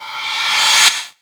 VEC3 Reverse FX
VEC3 FX Reverse 35.wav